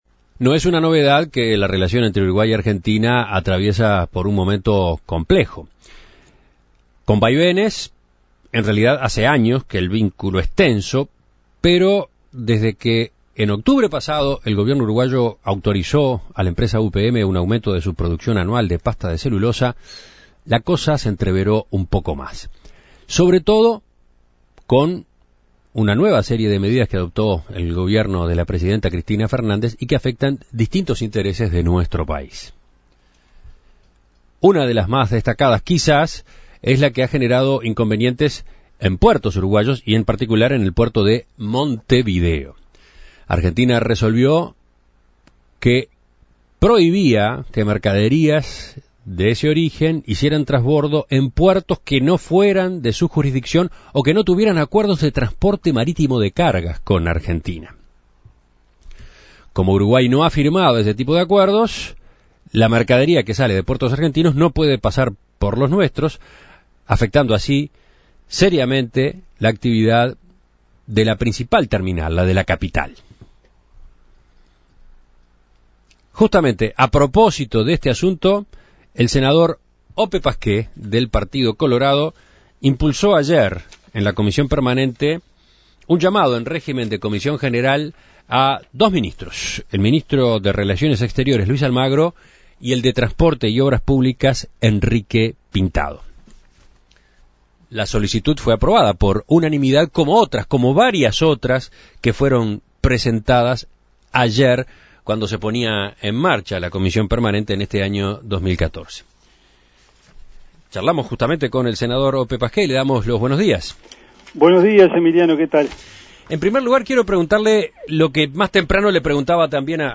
En el marco de la tensa relación con Argentina en los últimos meses y las resoluciones que perjudican los puertos de nuestro país, el senador colorado Ope Pasquet propuso un llamado en régimen de comisión general a los ministros Luis Almagro (Relaciones Exteriores) y Enrique Pintado (Transporte y Obras Públicas). A propósito de esta iniciativa, En Perspectiva consultó al senador de Vamos Uruguay.